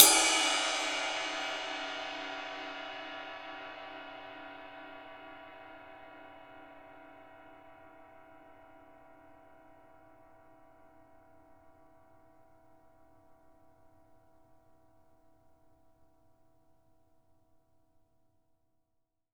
Index of /90_sSampleCDs/Sampleheads - New York City Drumworks VOL-1/Partition A/KD RIDES